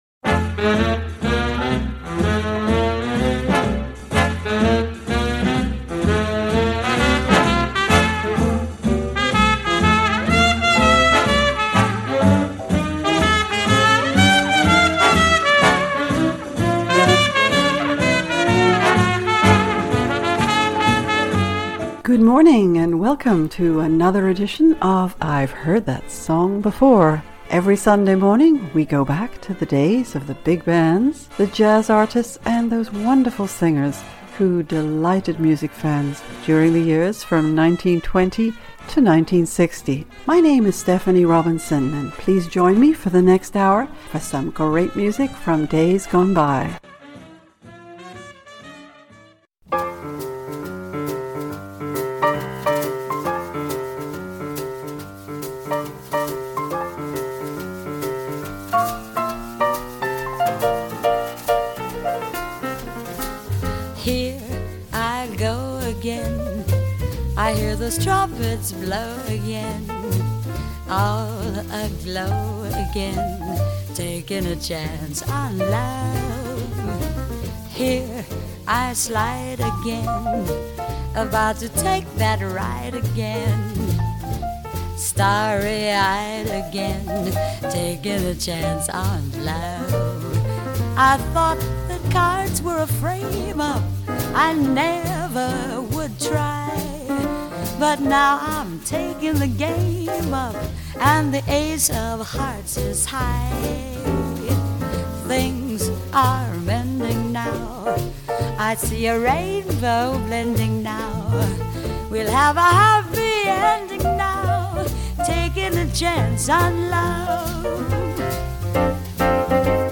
Enjoy some great music from the 1940s and 1950s!